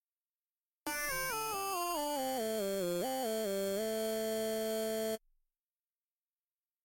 描述：在FL12的Nexus上的主音303。
Tag: 140 bpm Chill Out Loops Synth Loops 1.15 MB wav Key : F